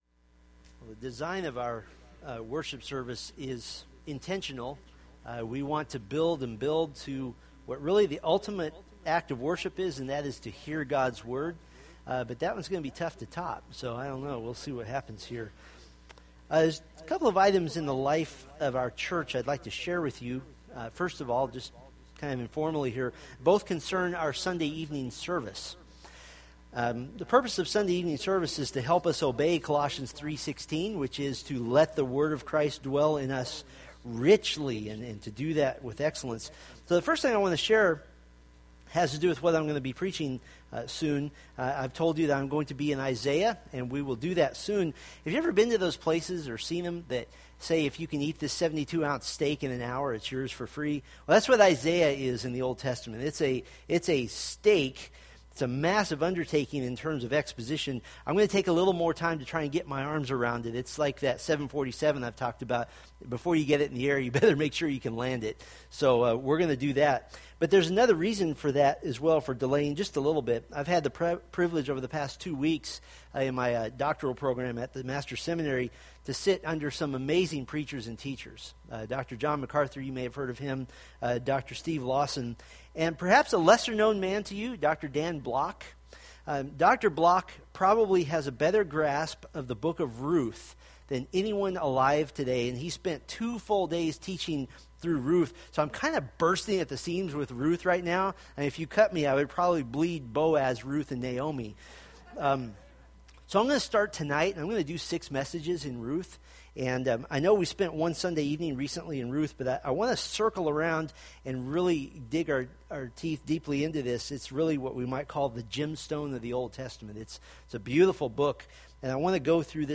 Colossians Sermon Series